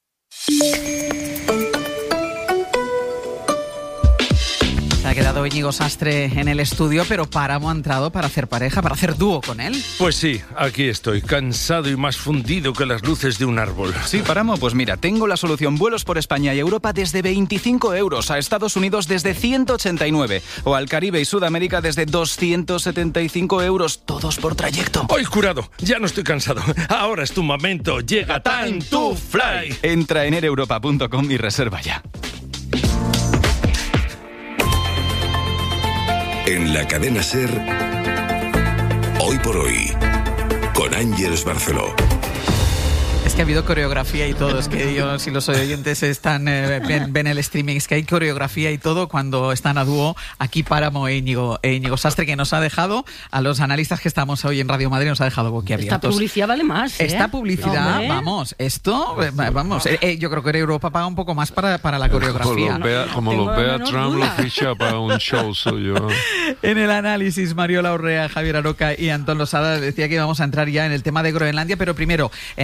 Mención publicitaria en directo de Air Europa
La publicidad es mucho más dinámica cuando hay interacción entre varios locutores y se integra dentro del espacio.